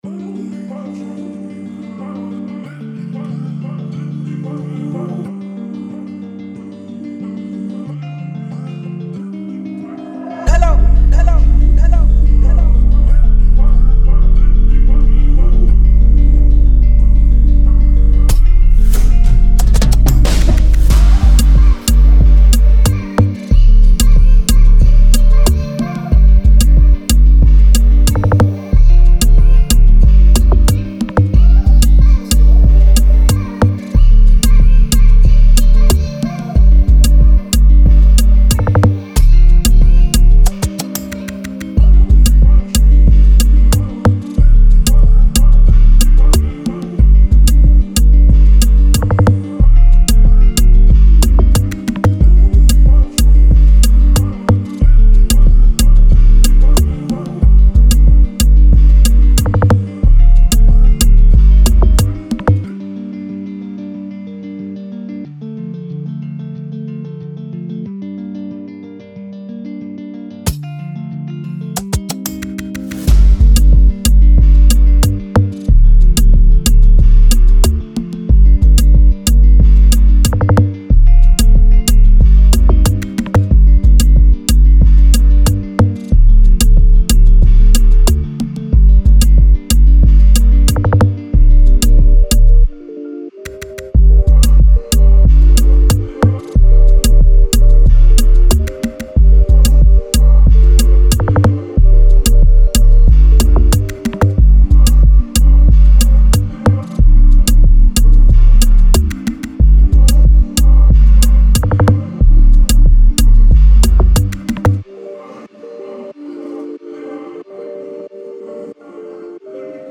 Beat